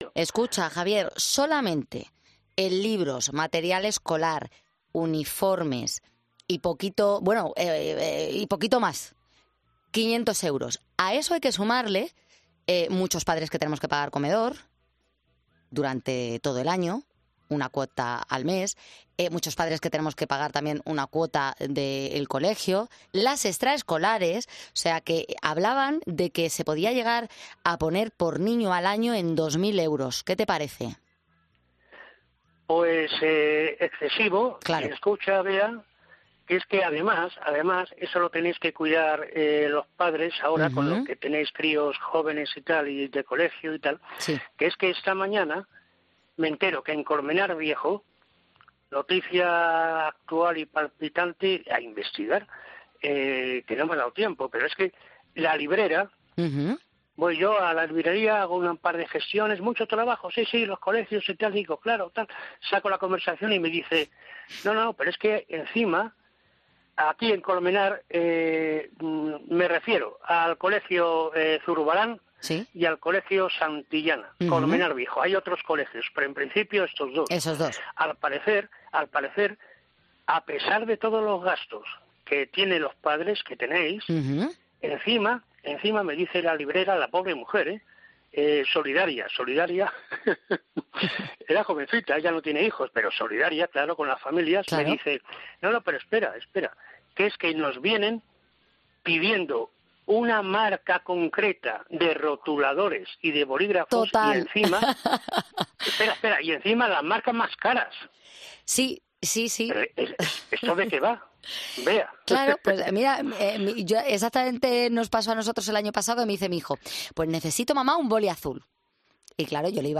Un experiodista oyente de Poniendo las Calles se quejó de esta petición de los colegios para los niños de la casa